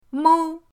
mou1.mp3